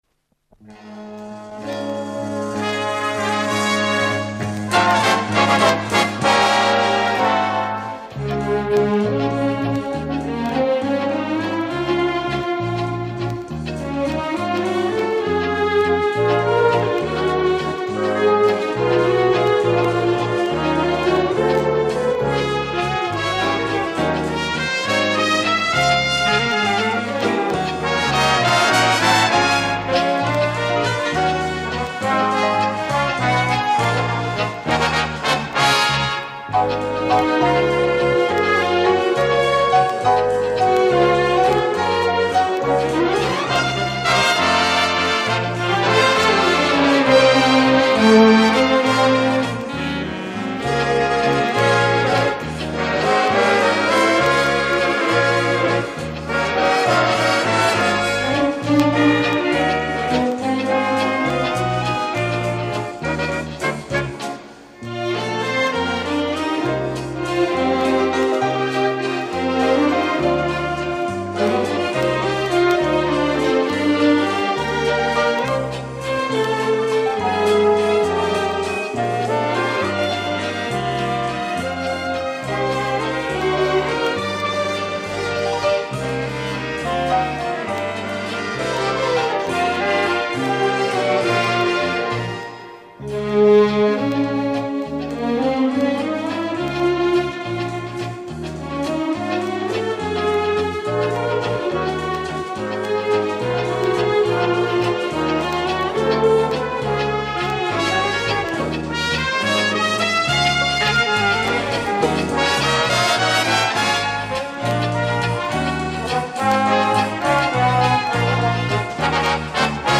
Две оркестровые пьесы в ритме вальса из Старого радио.
018.20-apr.-eso-vals.mp3